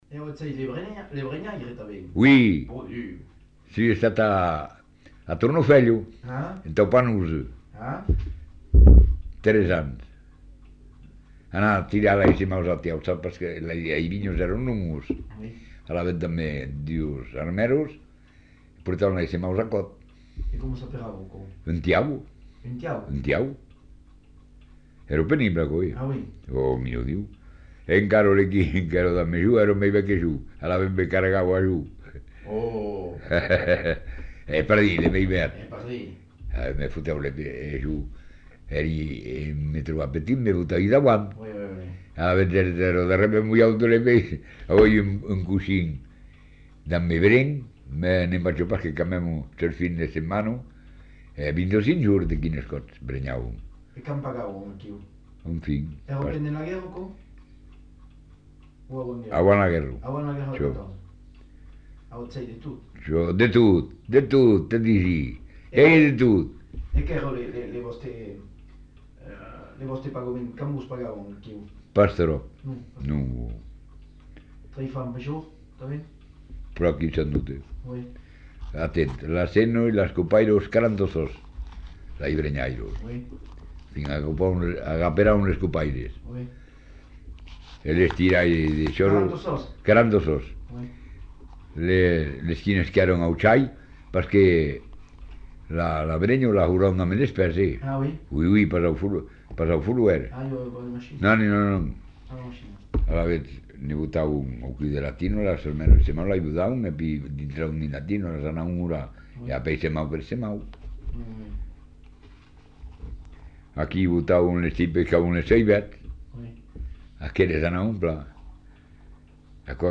Lieu : Saint-Thomas
Genre : récit de vie